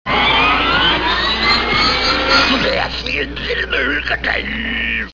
Шум вращения Тасманского дьявола